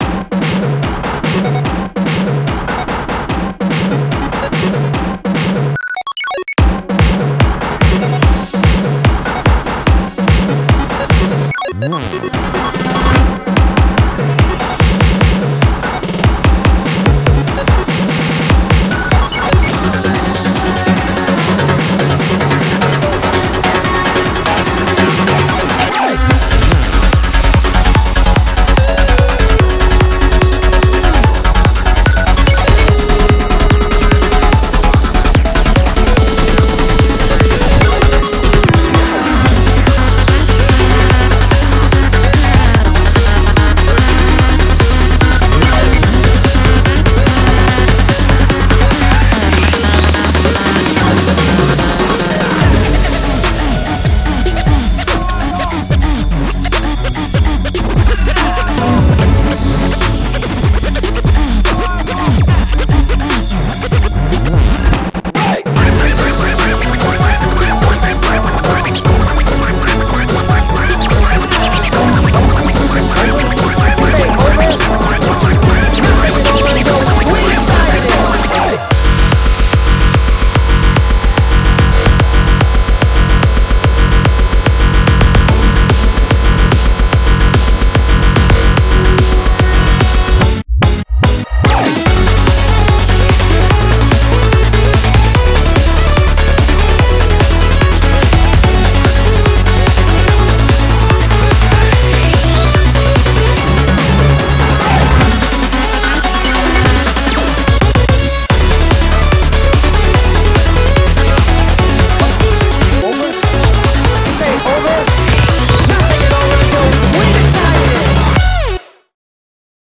BPM146
Audio QualityPerfect (High Quality)
Commentaires[HARD PSY]